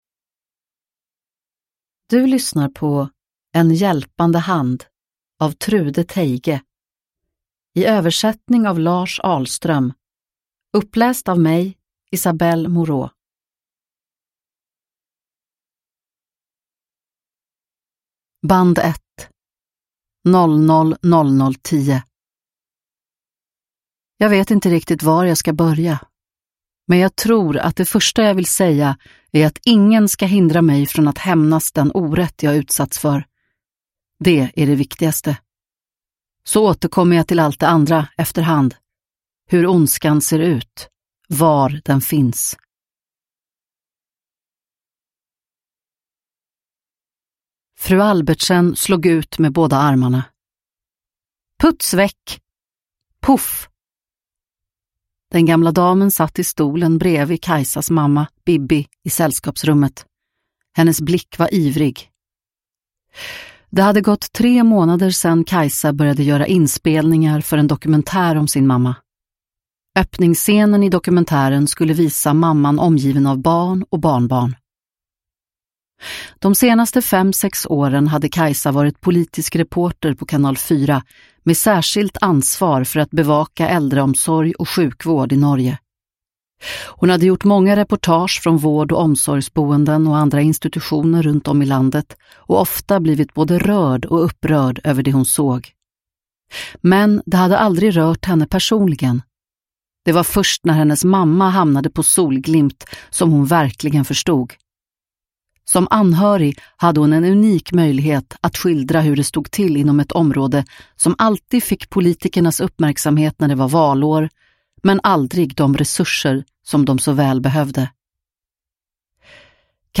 En hjälpande hand – Ljudbok – Laddas ner